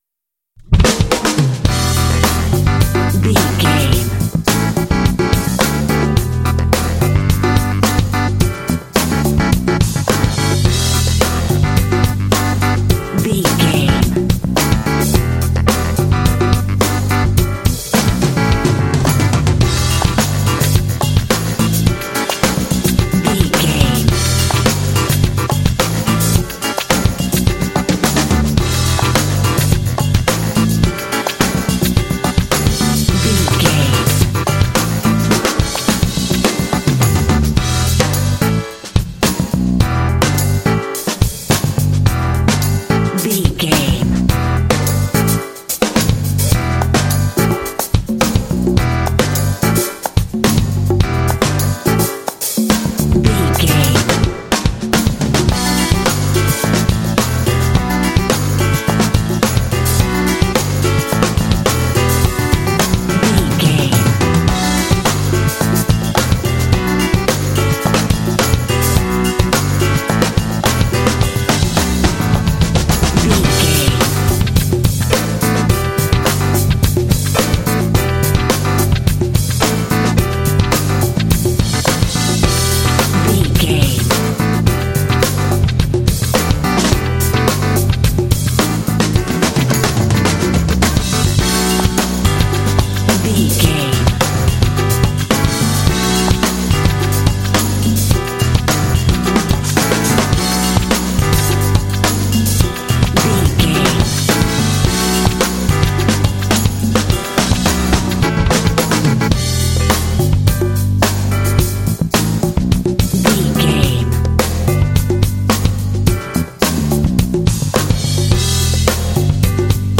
Uplifting
Dorian
groovy
driving
energetic
drums
bass guitar
electric guitar
saxophone
piano